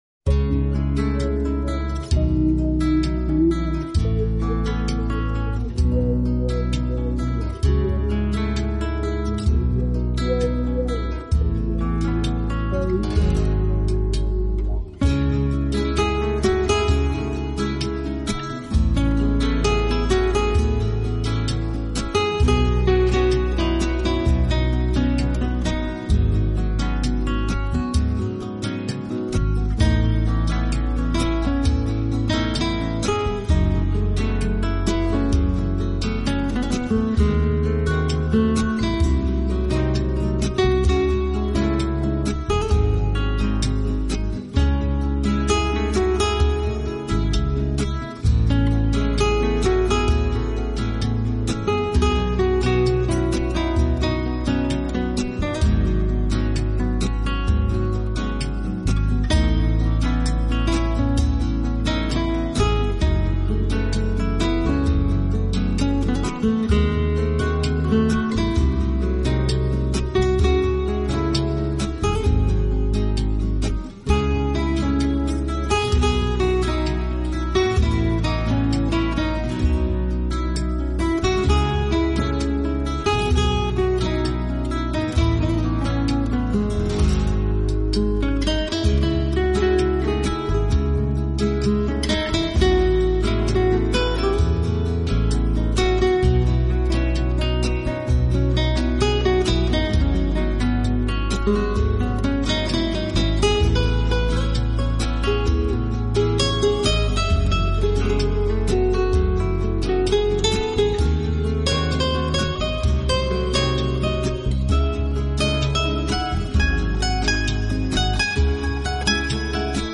Genre: New Age, Relax, Instrumental